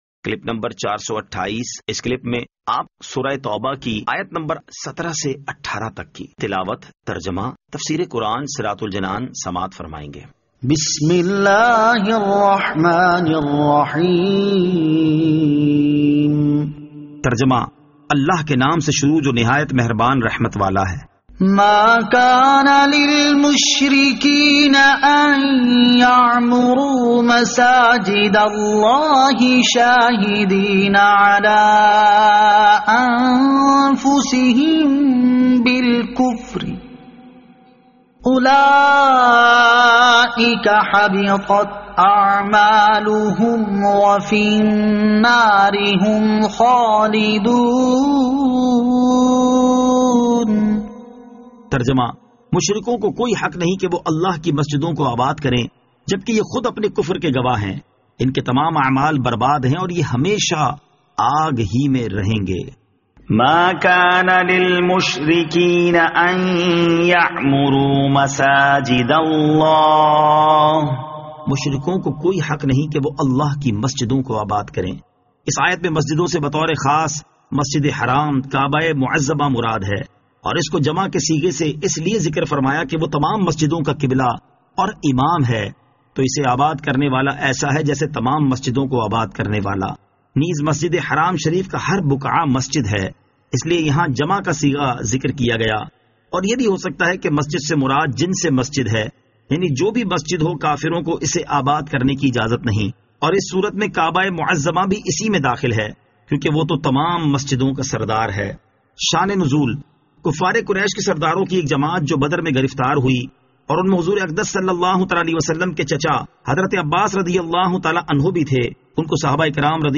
Surah At-Tawbah Ayat 17 To 18 Tilawat , Tarjama , Tafseer